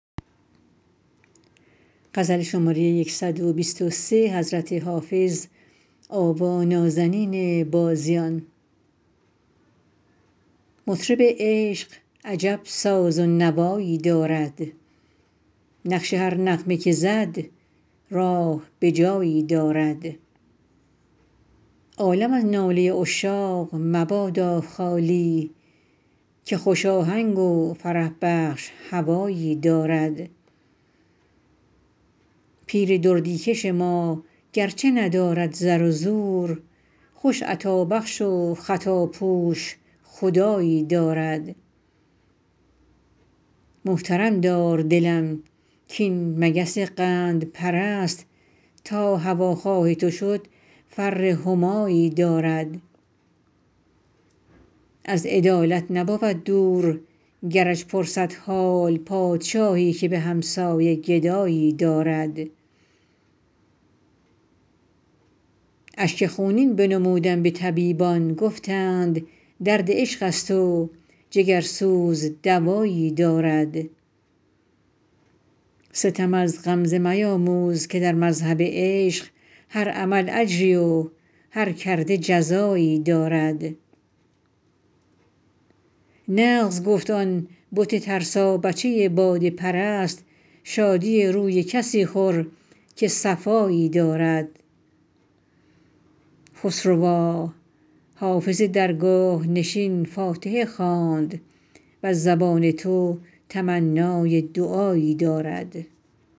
حافظ غزلیات غزل شمارهٔ ۱۲۳ به خوانش